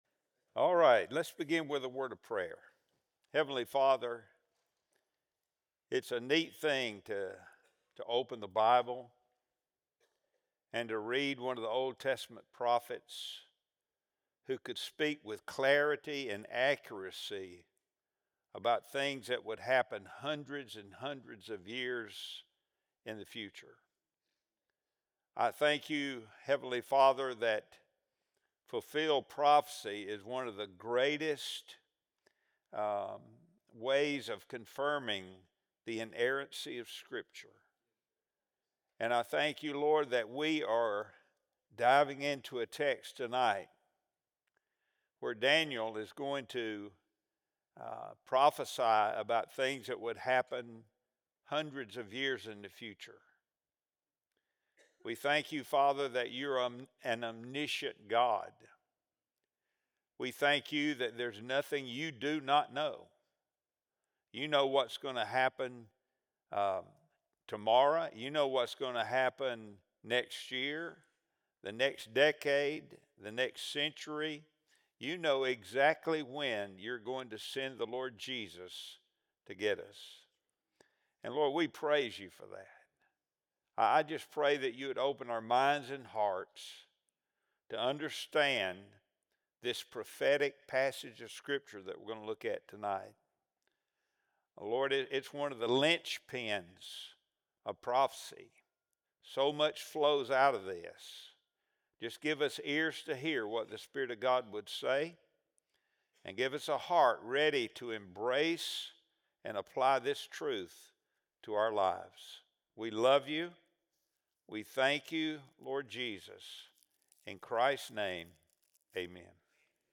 Wednesday Bible Study | September 21, 2025